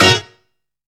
HICCUP HIT.wav